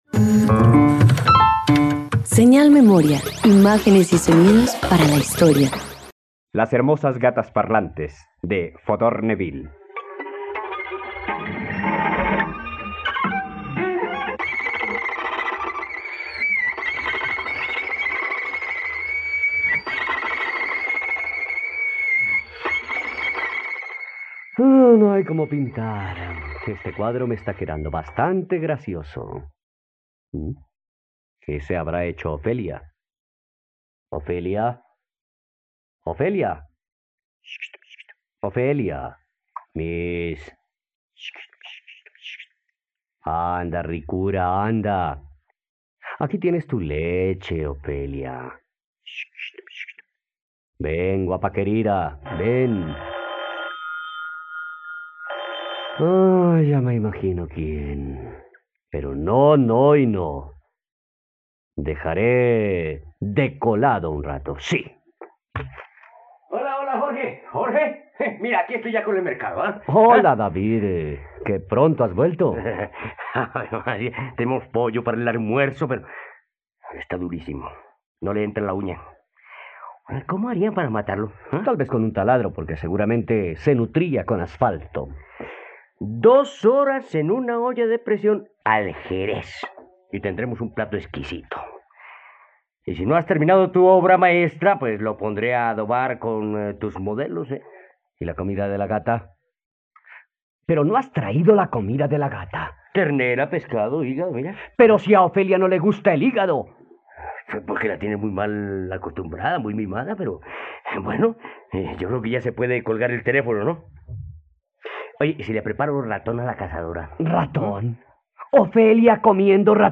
Las hermosas gatas parlantes - Radioteatro dominical | RTVCPlay